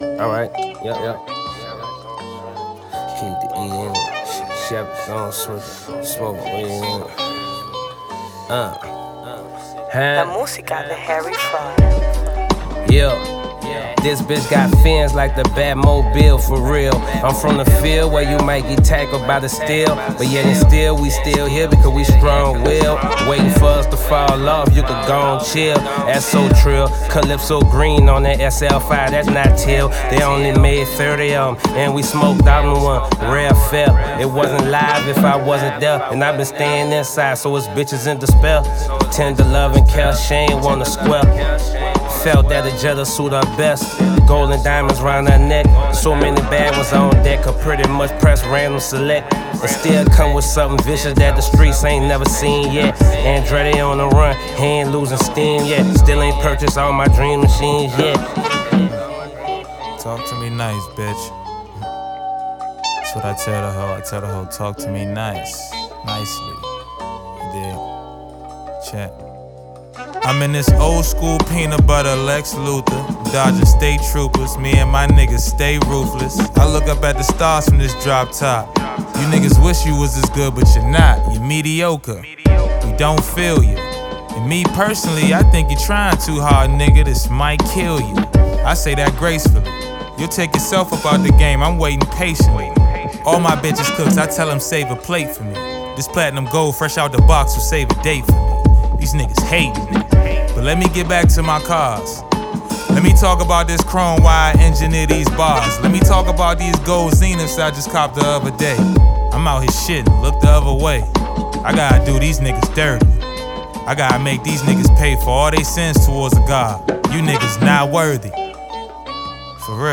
Hip Hop